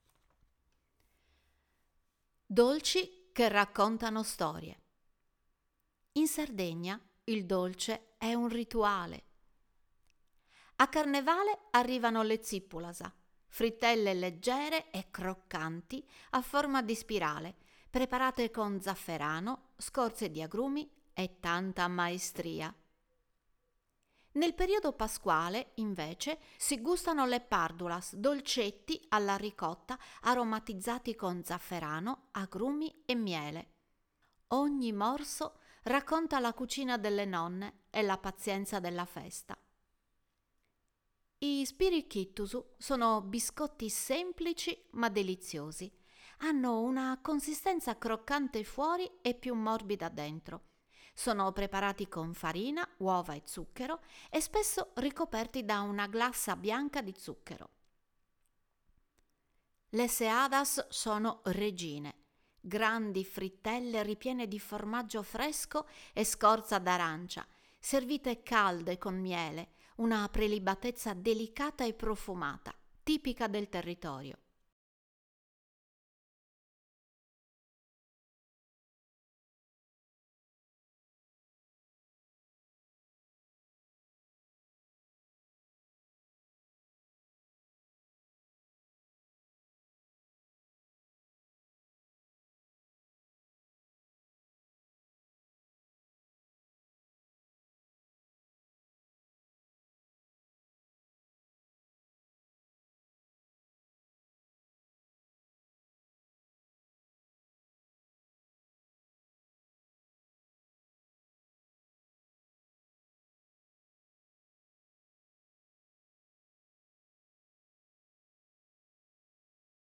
Voce Narrante
🎧 Audioguida - Sapori della Trexenta